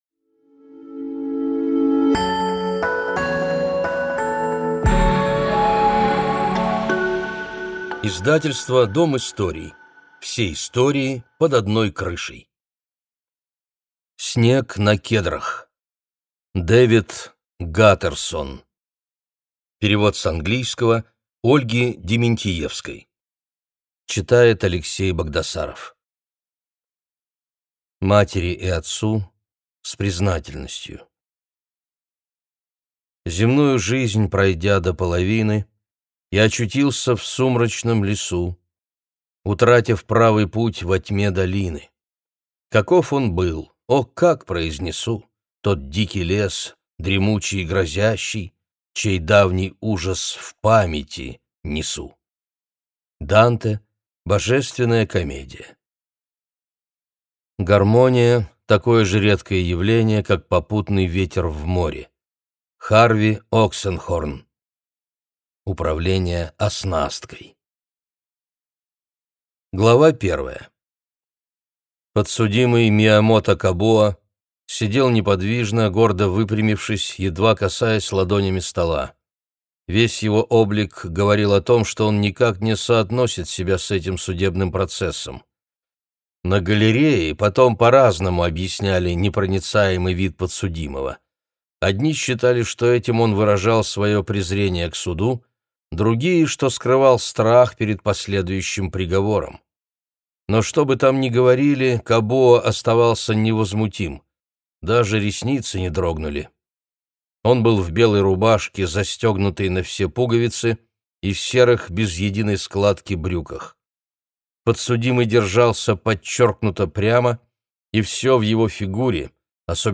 Аудиокнига Снег на кедрах | Библиотека аудиокниг